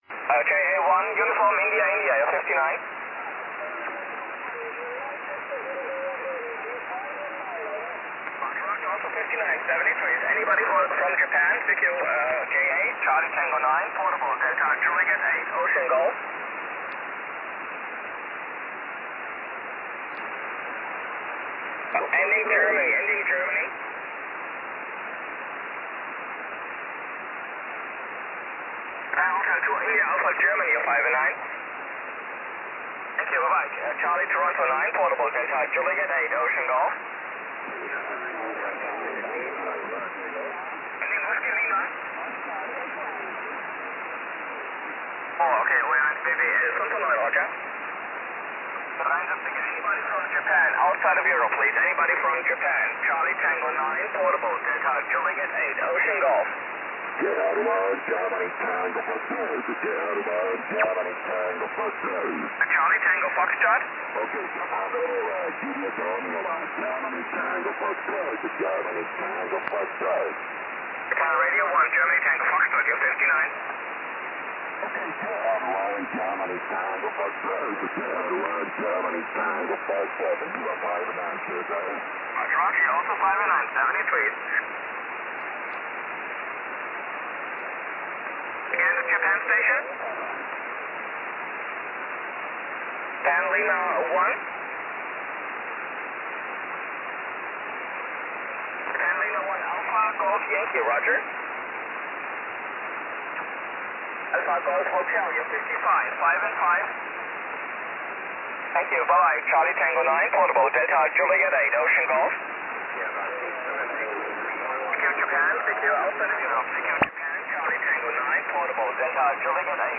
18.146MHz SSB